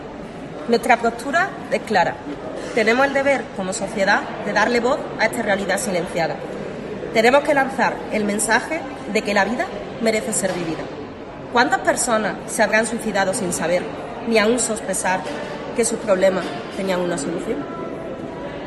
La concejal Sara Ríos participa en la lectura del manifiesto conmemorativo del Día Mundial de la Salud Mental 2019 desarrollada en el Hospital de Antequera
Cortes de voz